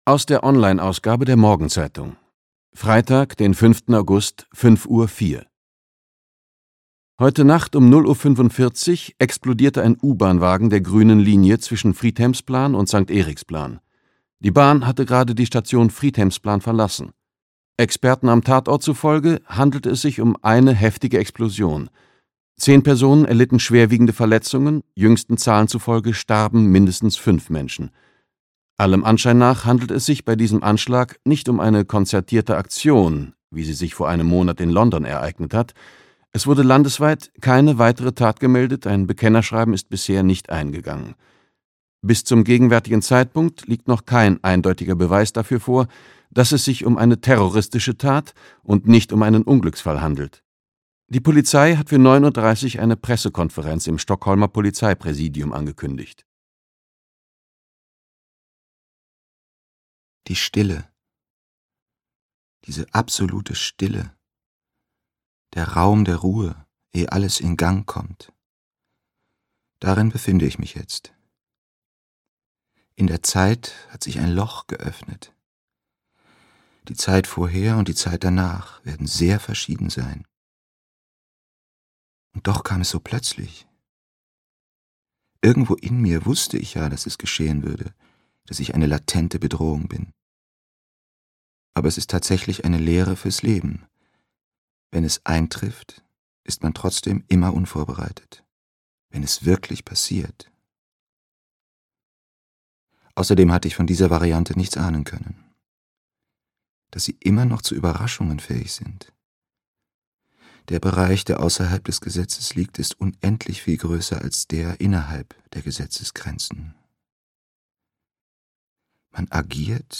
Opferzahl (A-Team 9) - Arne Dahl - Hörbuch